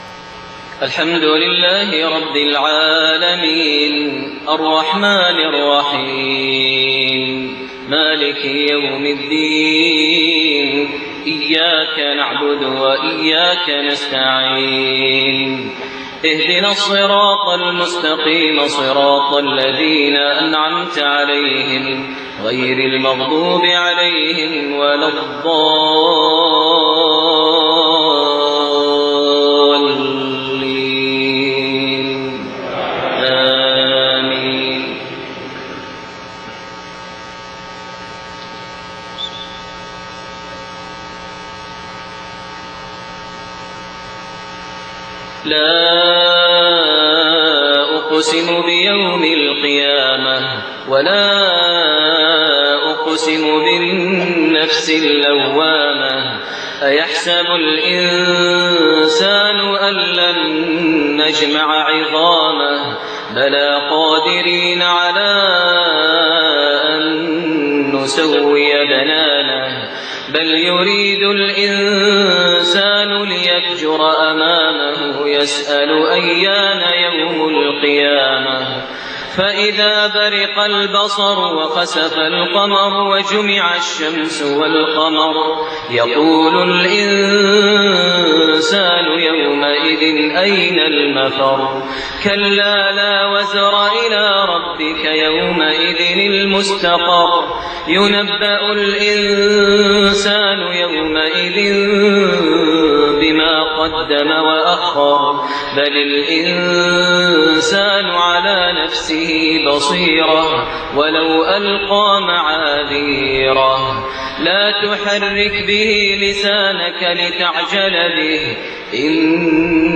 Isha prayer from Surat Al-Qiyaama and Al-Balad > 1429 H > Prayers - Maher Almuaiqly Recitations